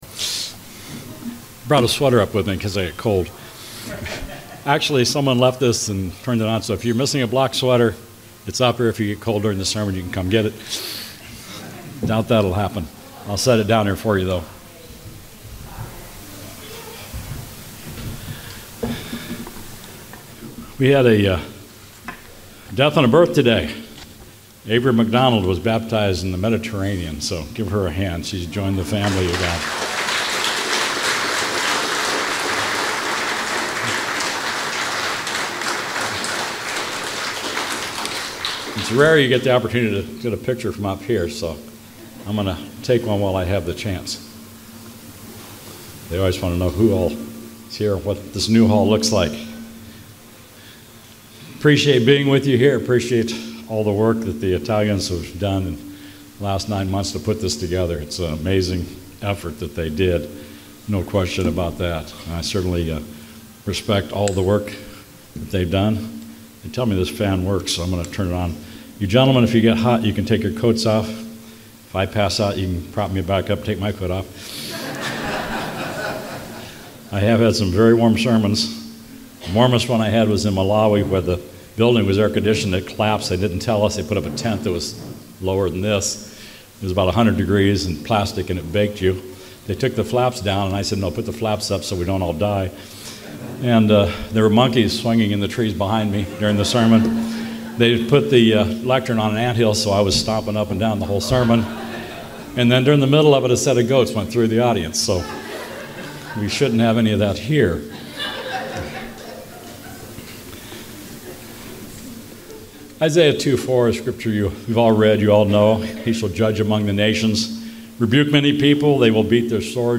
FoT 2024 Marina di Grosseto (Italy): 1st day